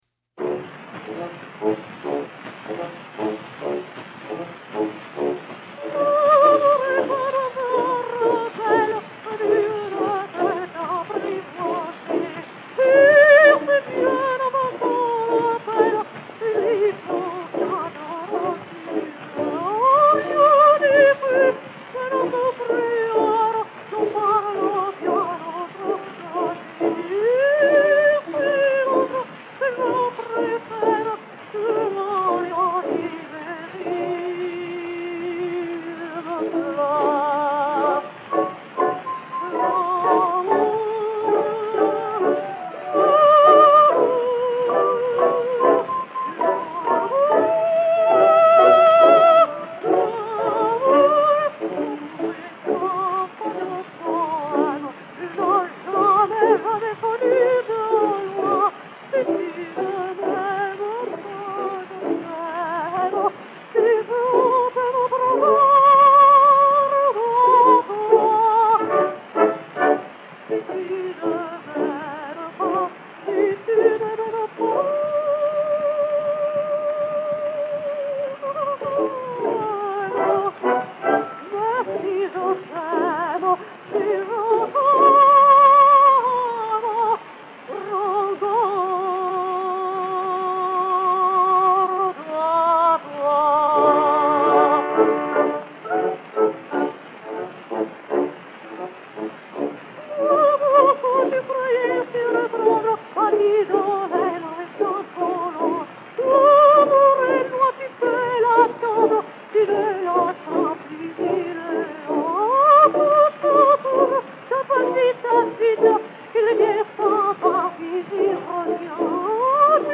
Madame Lina Cavalieri possède un véritable secret pour les poétiser, non seulement par le charme de sa voix, qui est souple, ample et sonore, mais par la beauté de son expression et cette distinction de race qui lui est vraiment spéciale et qui frappe toujours en elle dès le premier abord.
Lina Cavalieri (Carmen) et Orchestre
Columbia A 5179, mat. 30372, enr. à New York le 01 mars 1910